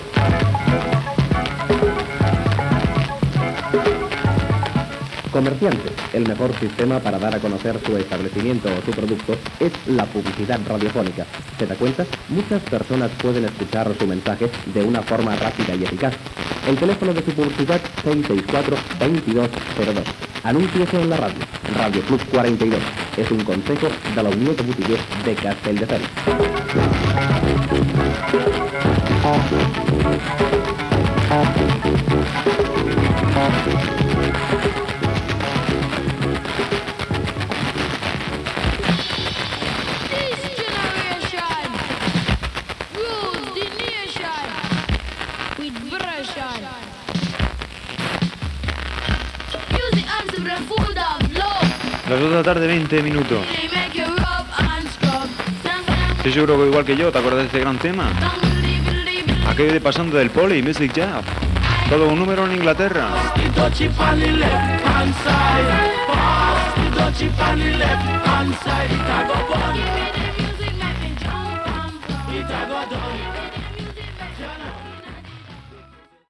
Publicitat per anunciar-se a l'emissora i presentació d'un tema musical
FM